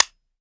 weap_beta_disconnector_plr_01.ogg